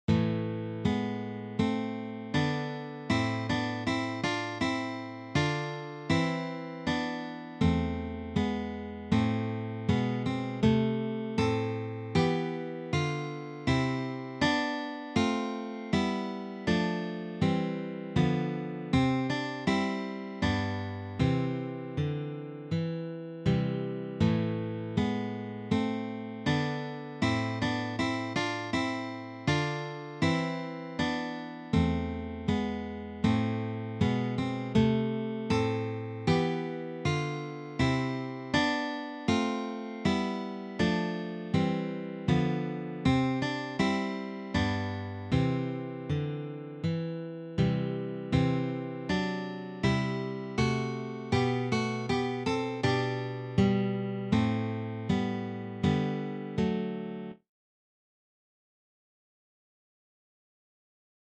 This is from the Baroque period.